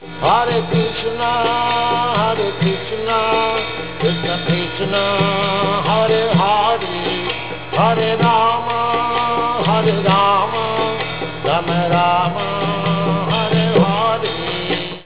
Presione aquí y escuche el maha-mantra Hare Krishna (wav) cantado por Srila Bhaktivedanta Swami Prabhupada